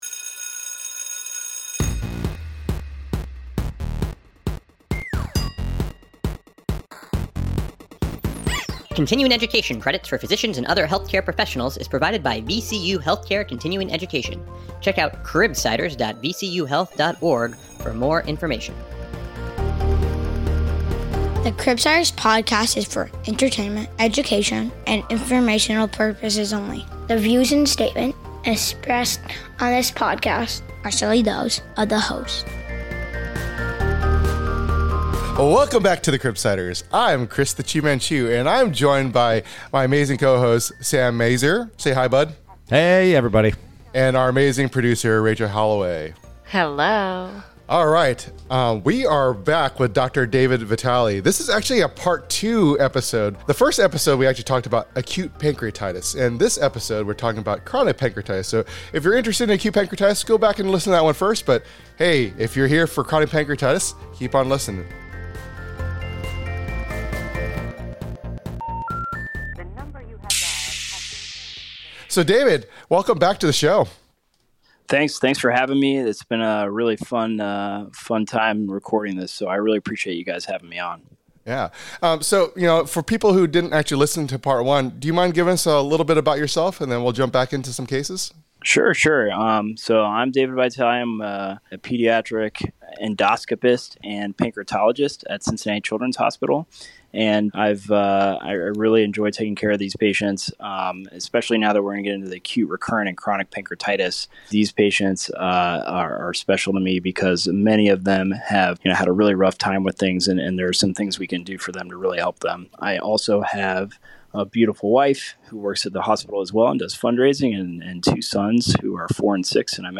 Join us for part 2 of our informative discussion